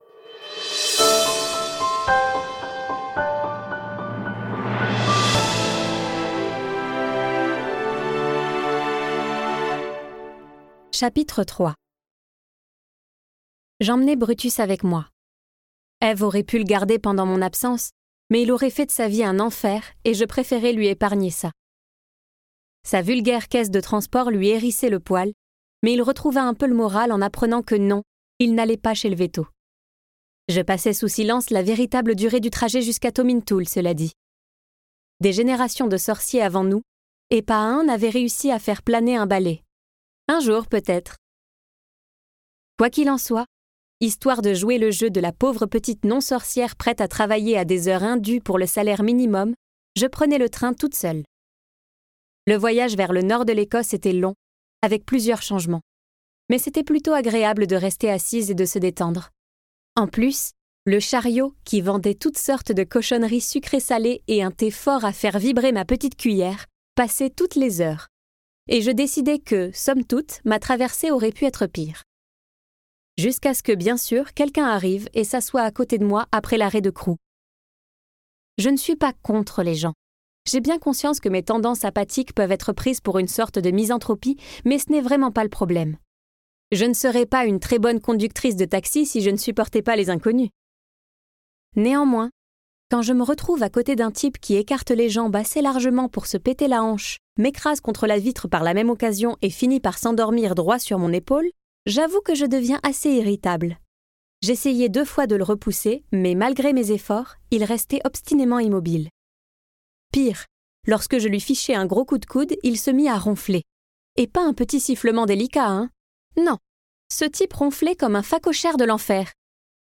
Glander sur un plateau télé, c'est sûrement pas difficile... ni dangereux... n'est-ce pas ?Ce livre audio est interprété par une voix humaine, dans le respect des engagements d'Hardigan.